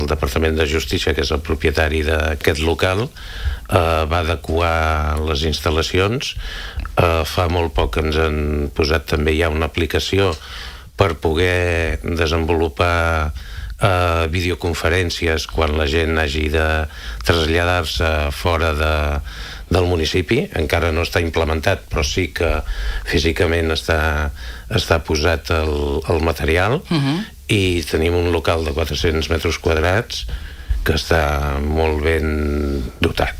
Ho ha explicat Francesc Xavier Ten, titular del jutjat de pau de Calella, en una entrevista a l’FM i +: